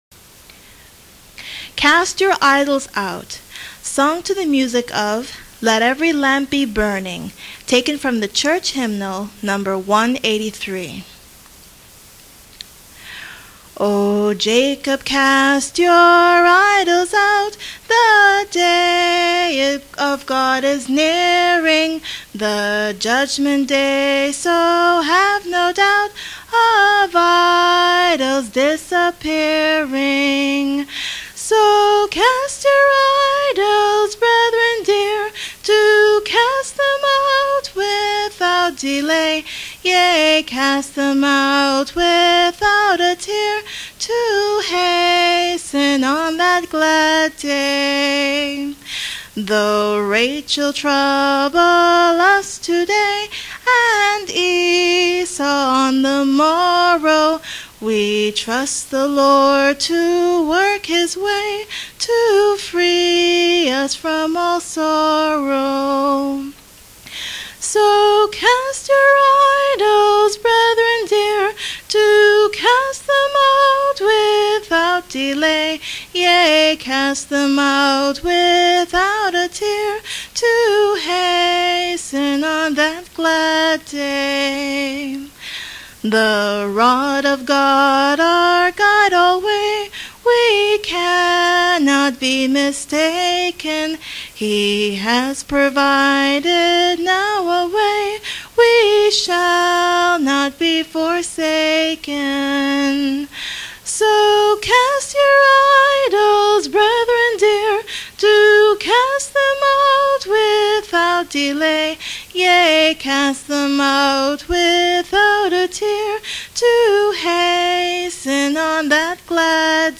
Sung without instrumental accompaniment